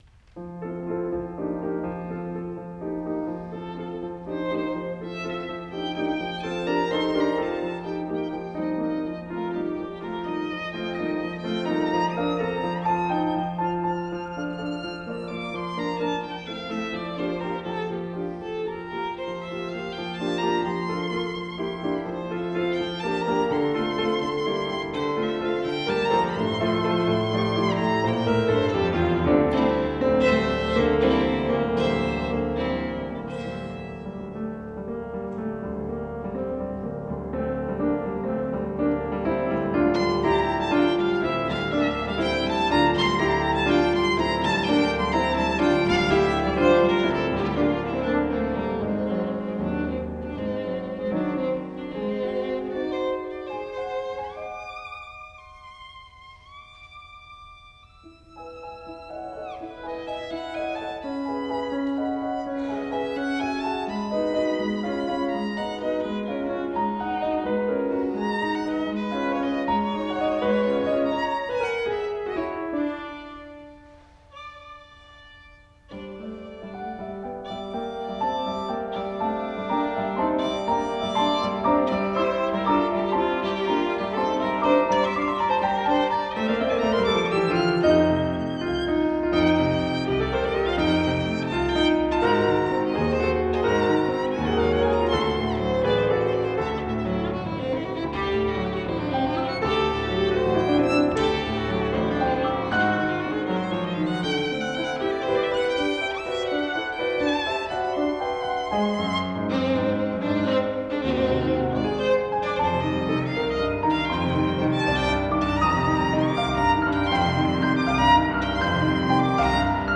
composed for violin and piano, August 6�August 23, 2014
Recording is the version for violin and piano
I�ve always been partial to waltzes and waltz-like music, which can be found throughout my works from the earliest years.
Violin_Piano_WFOT_1-11-15.mp3